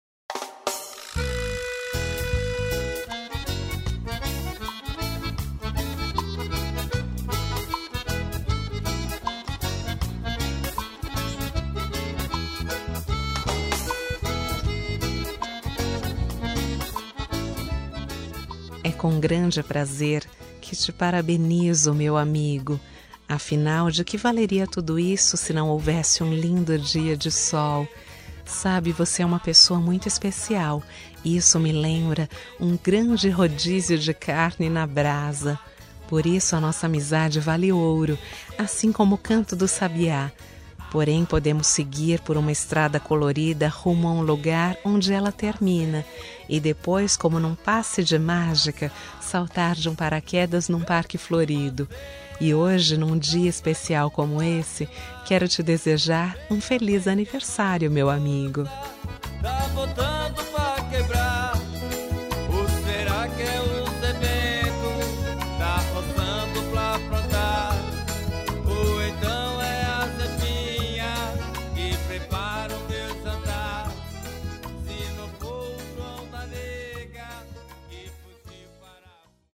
Aniversário de Humor – Voz Feminina – Cód: 200106